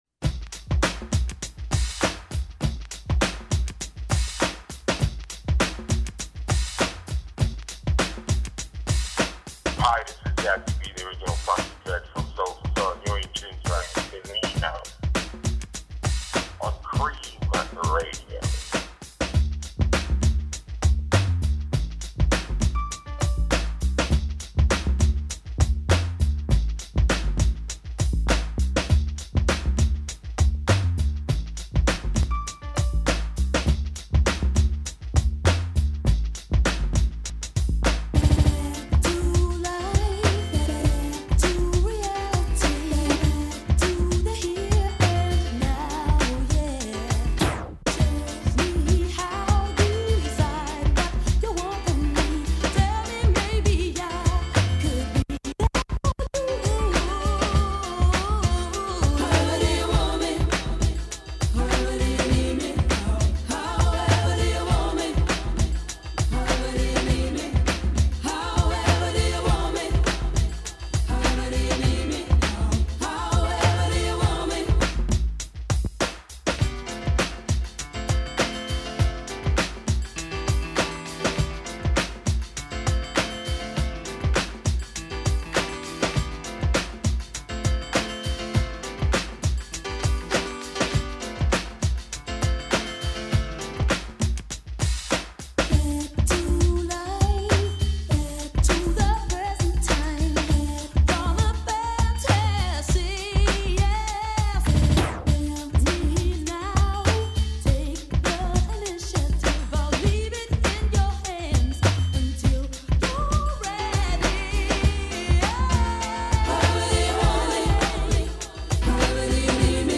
Interview with Jazzie B of Soul II Soul who tour Australia in March 2023